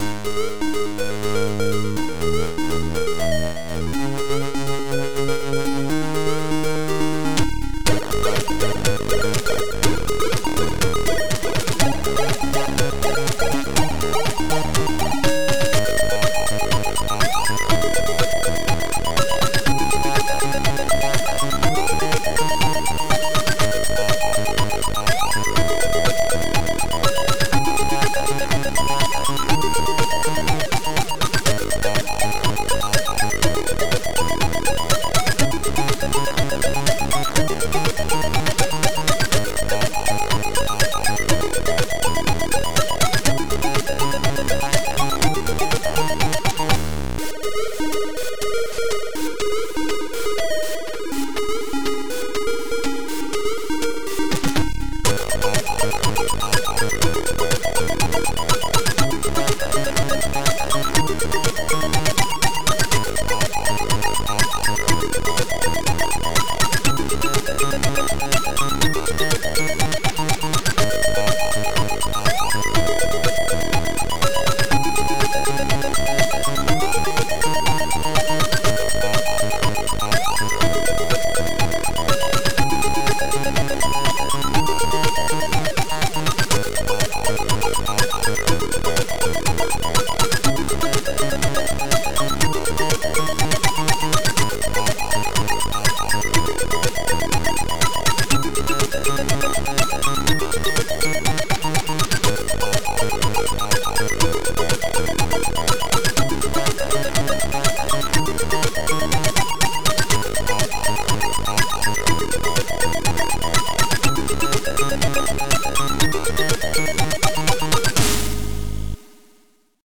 ABC YM2149F